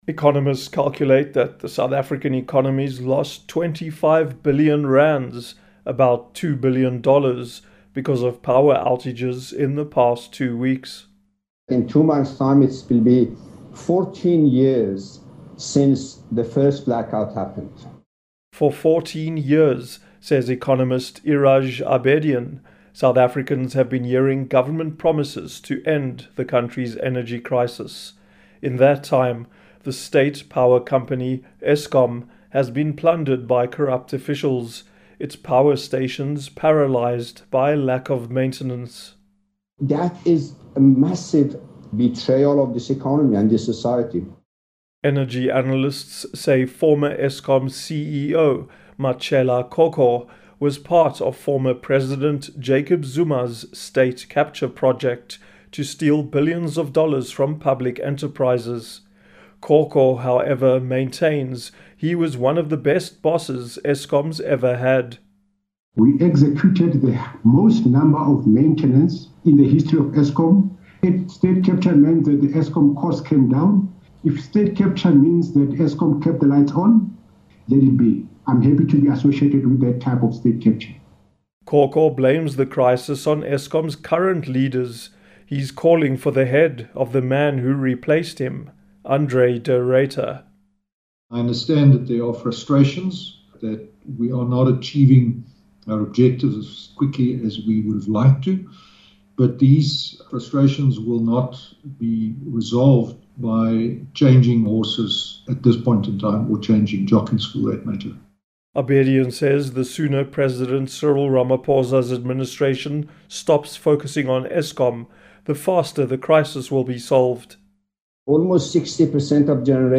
South Africa’s national electricity company, Eskom, is desperately trying to turn the lights back on after two weeks of rolling blackouts. For Africa News Tonight